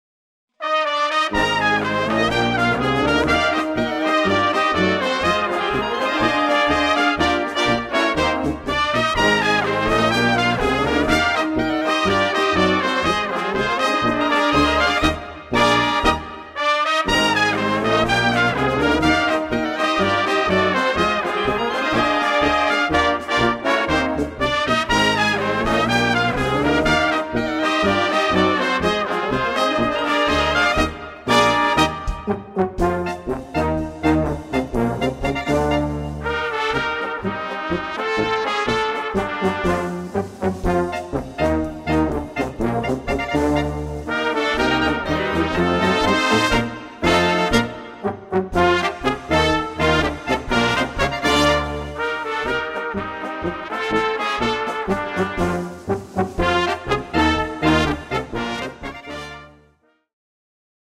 moderne Polka
Blasorchester